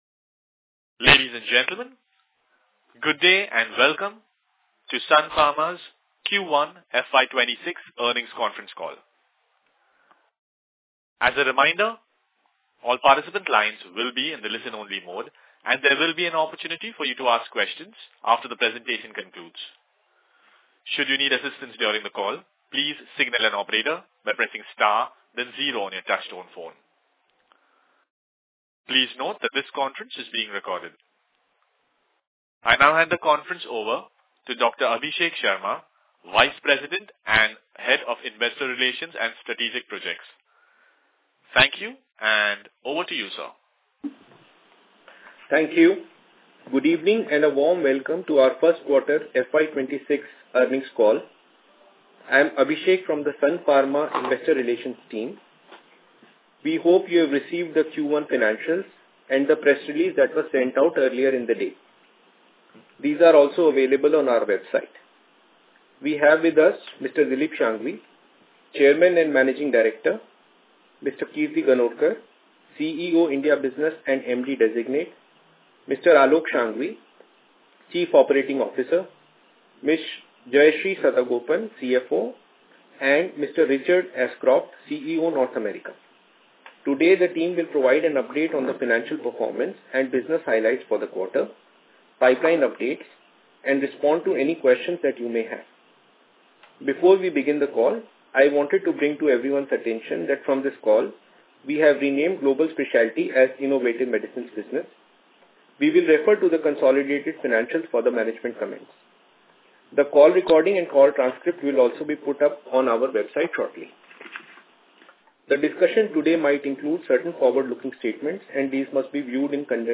Earnings Call Audio Recording